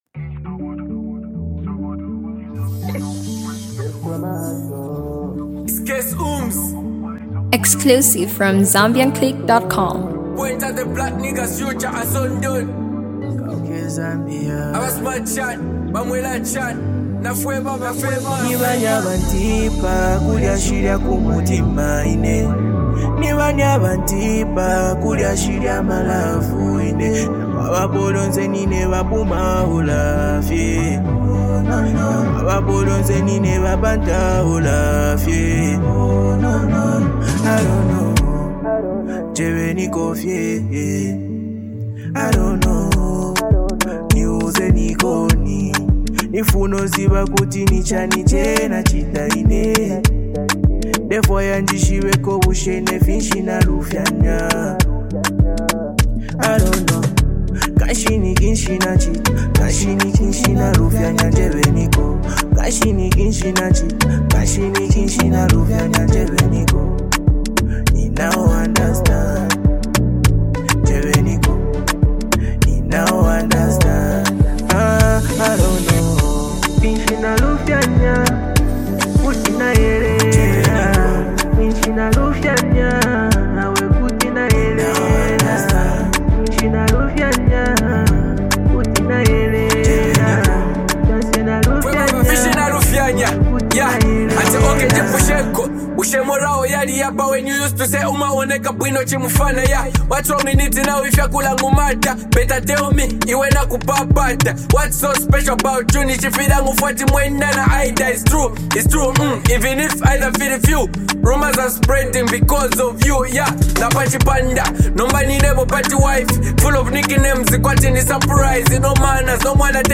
love song
love melody song